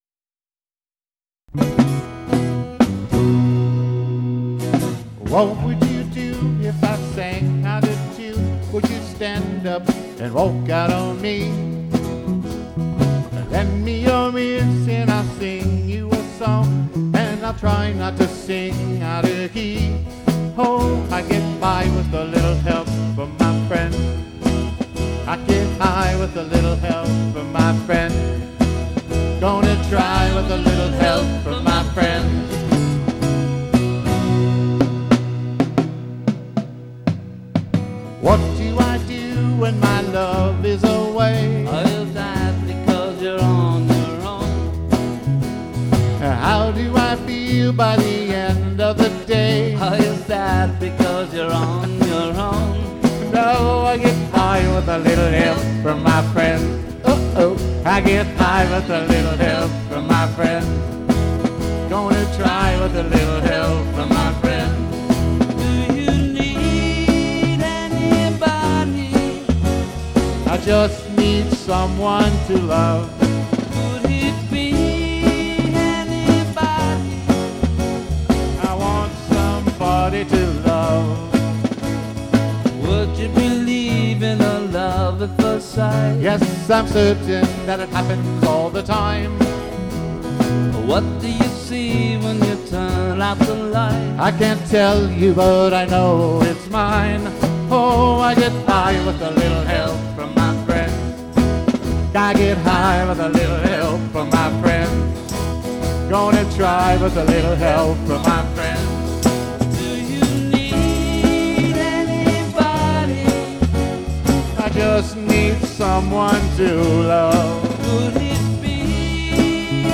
Category Rock/Pop
Studio/Live Live
12-string and 6 string guitars and vocals
drums
guitar and vocals
Percussion
backing vocals
bass